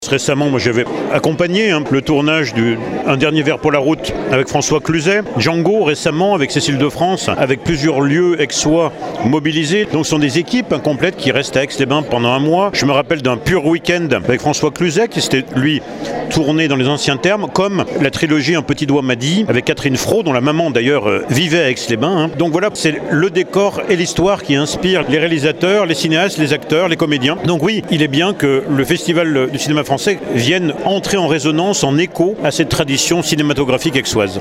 Aix les Bains aura souvent servi de lieux de tournage de films français par le passé, parfois en toute discrétion comme le rappelle Renaud Beretti le maire de la commune: